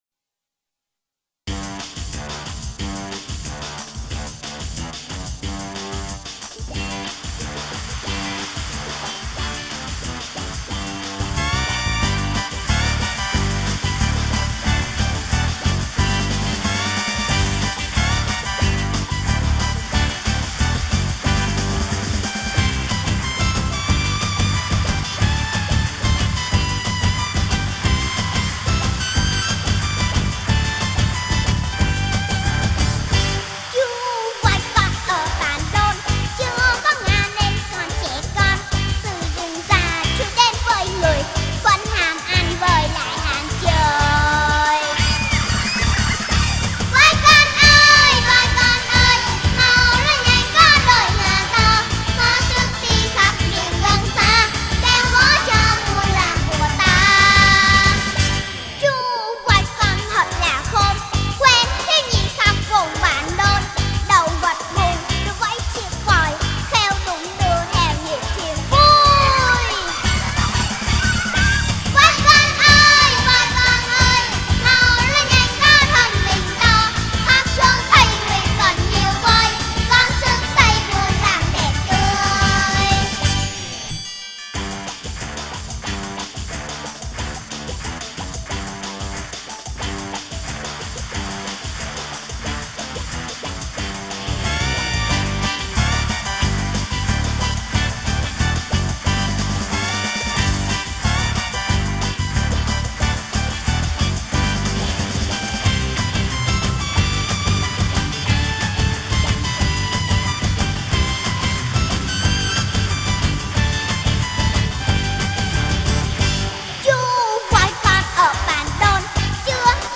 Sách nói | Chú Voi Con